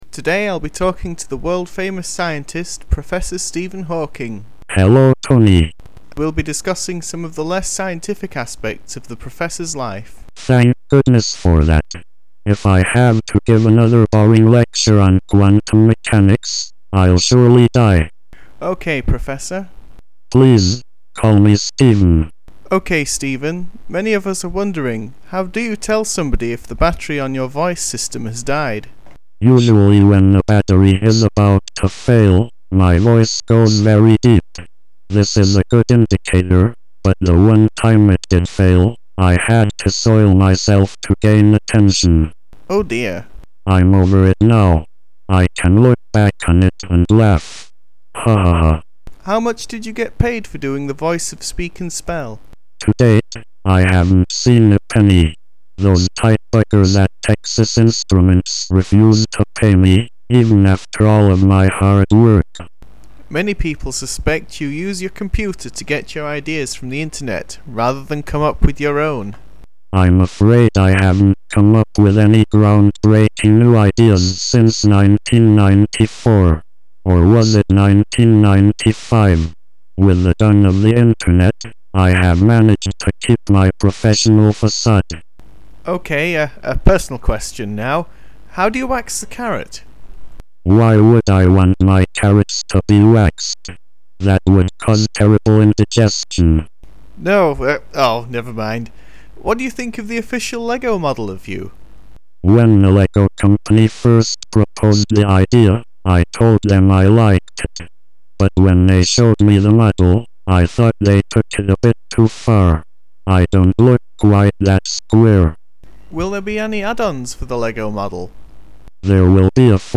StephenHawkingInterview.mp3